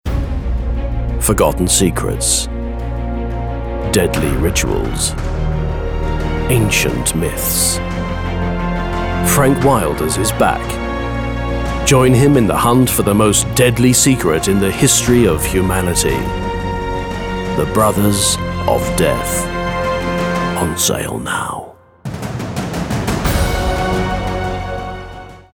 Movie Trailer